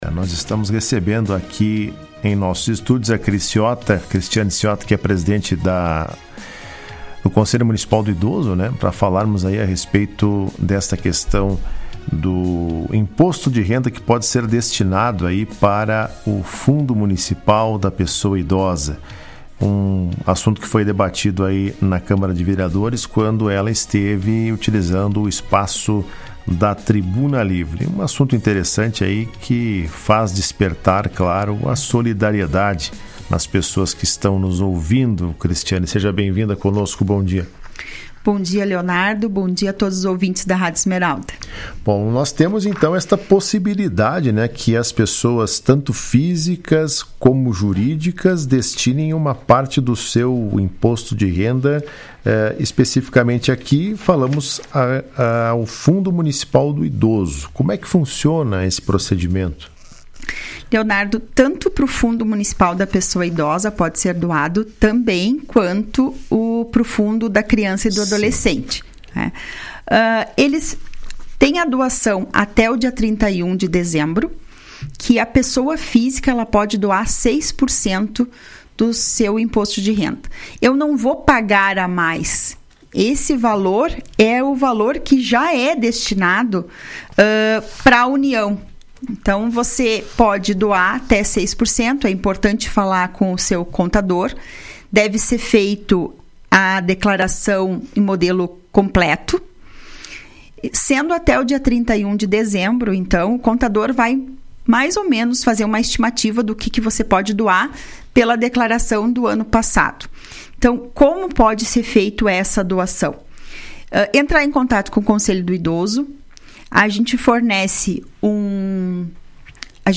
Em entrevista à Rádio Esmeralda nesta quinta-feira, ela explicou que pessoas físicas podem destinar até 6% do imposto devido até 31 de dezembro, e pessoas jurídicas até 1%, sem qualquer custo adicional, já que o valor apenas deixa de ser enviado ao governo federal e permanece investido em Vacaria.